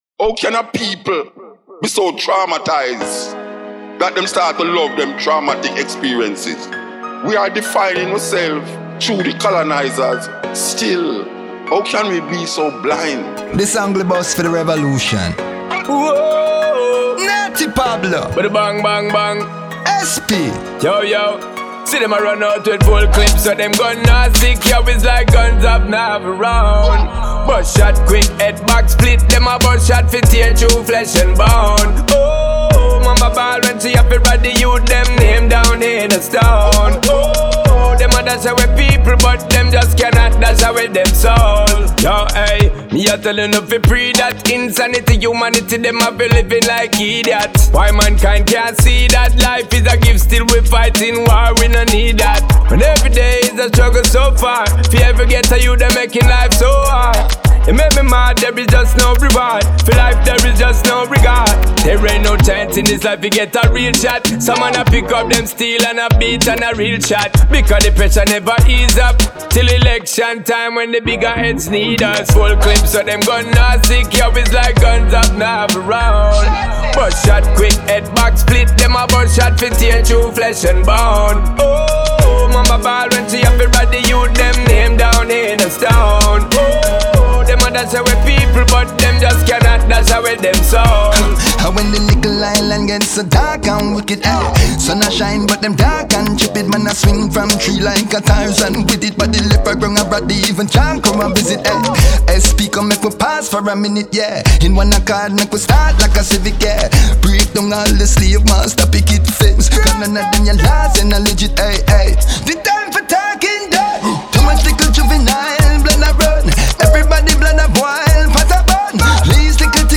энергичная регги-композиция